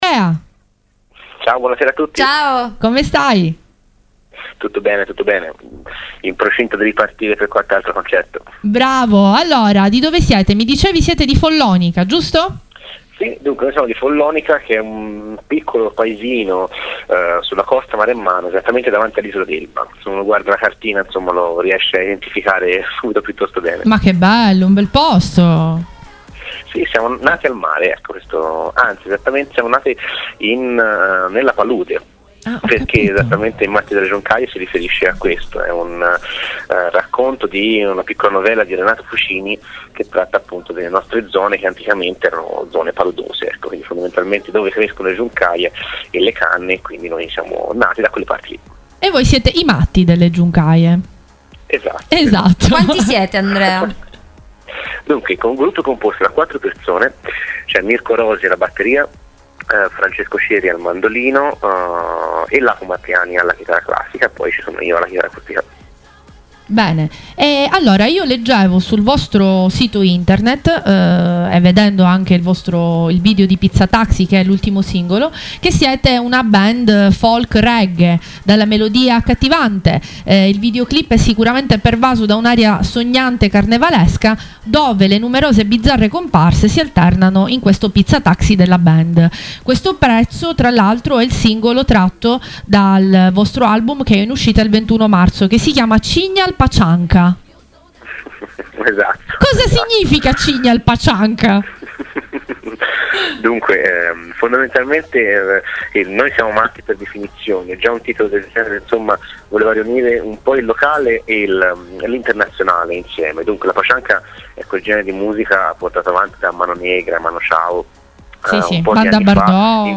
Il Cannolo lanciato nello spazio. Intervista ai protagonisti dell'impresa